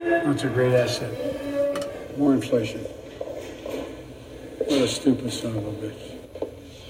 Push the button on his back, and you get to hear the Best of Joe Biden.